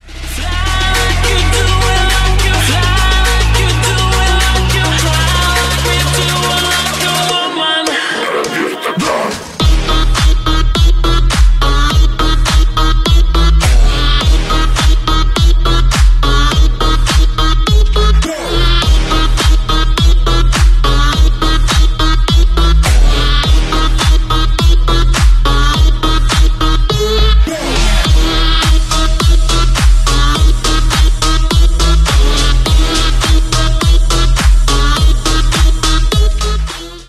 Ремикс # Танцевальные
клубные # громкие